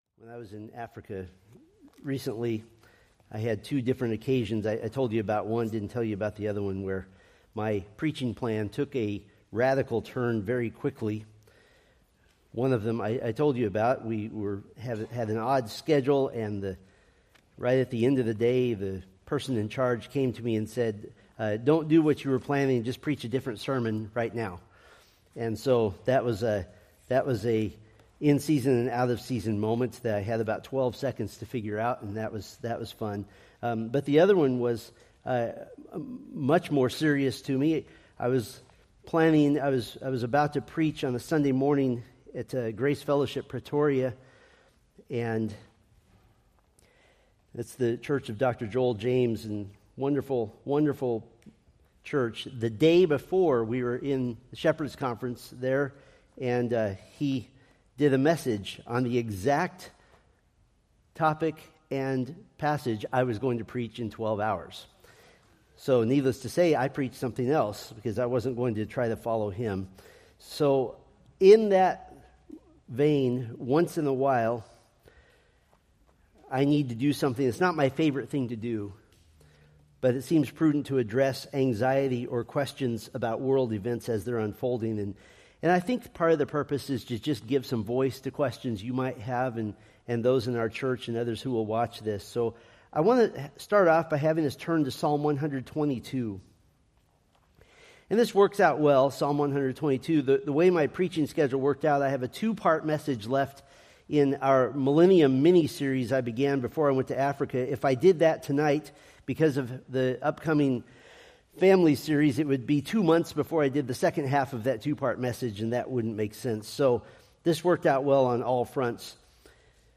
Preached June 22, 2025 from Selected Scriptures